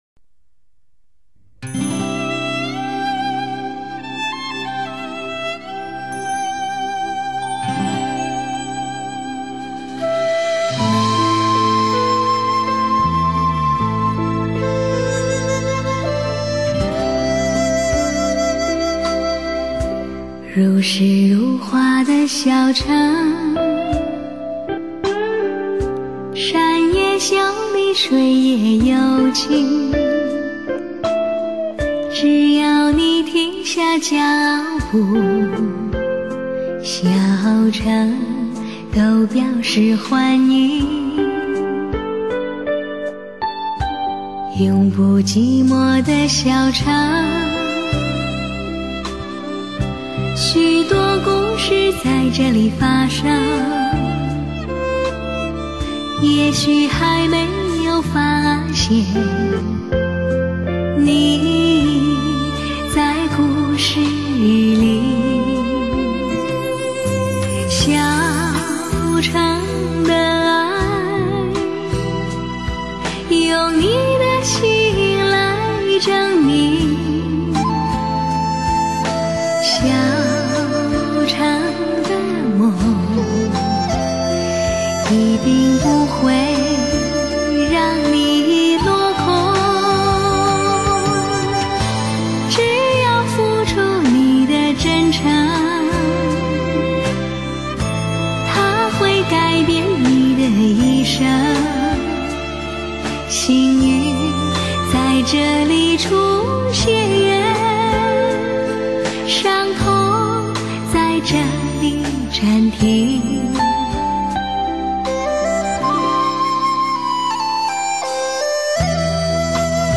全方位360度环绕HI-FI AUTO SOUND 专业天碟！打
制作手法HI-FI极致特色的女声！